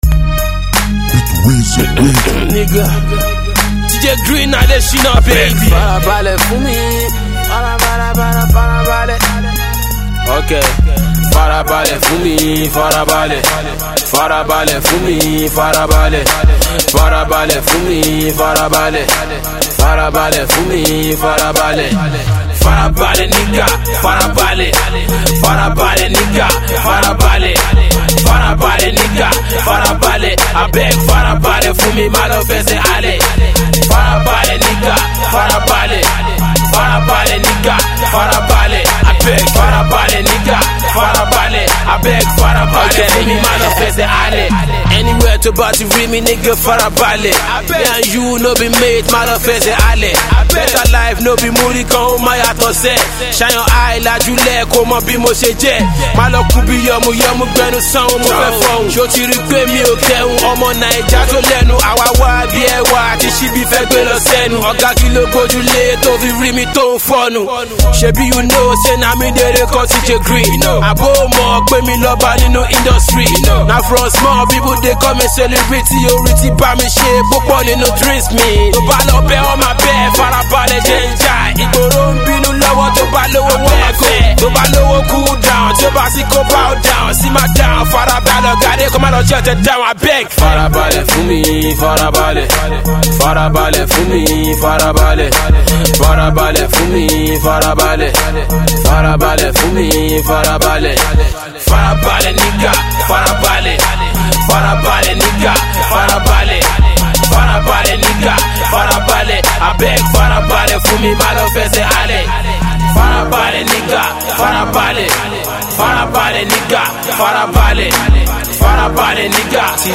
Indigenous Yoruba Rapper
Street Rap